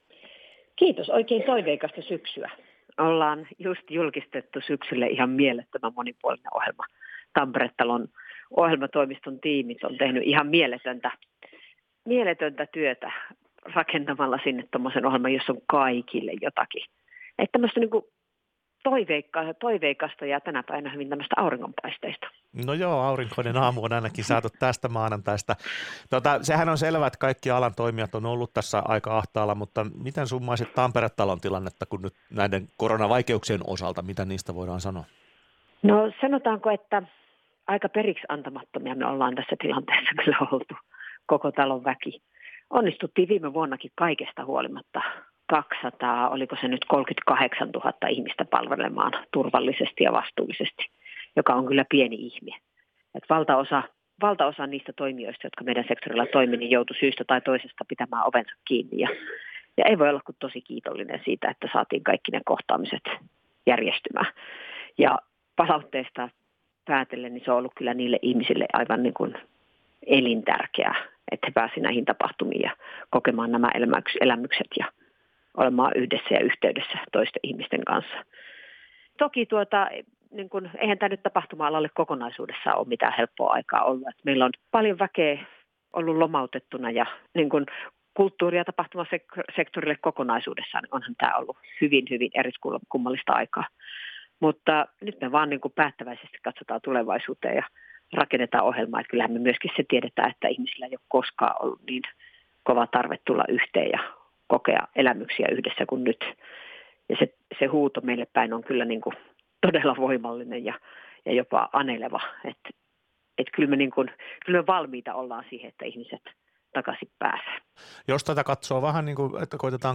Päivän haastattelu